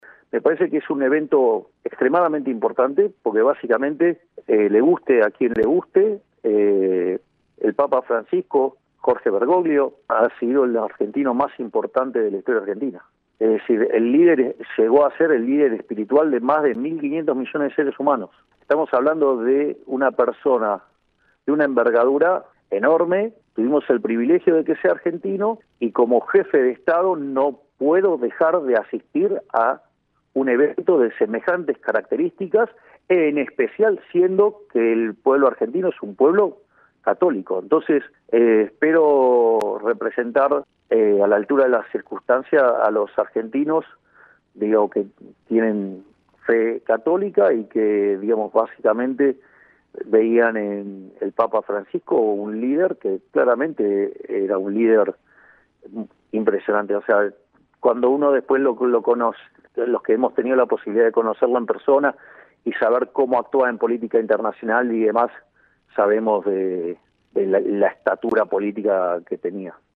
La declaración tuvo lugar en un programa de radio porteño donde el mandatario se refirió a su viaje a Roma para despedir al Sumo Pontífice.